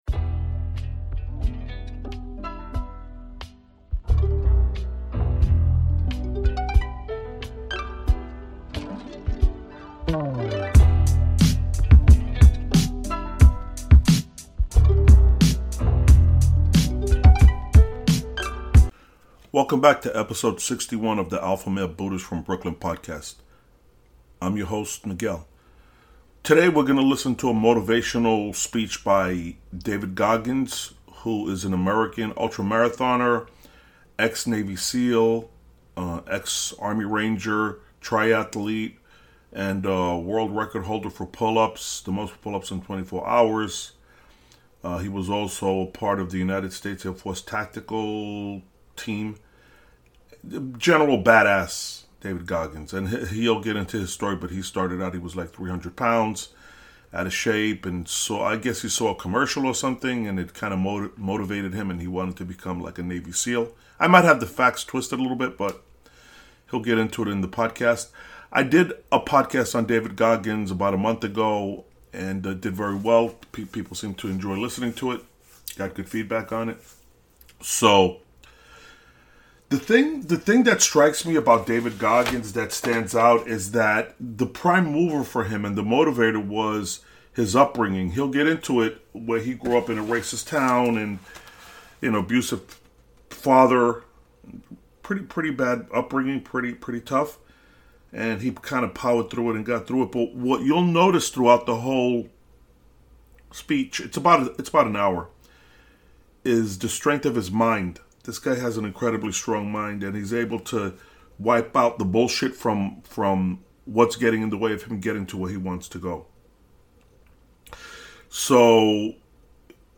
EP 61- David Goggins *** MUST LISTEN ***Motivational Speech from former Navy Seal who started at the bottom in life to become real life SUPERMAN-Power of Mind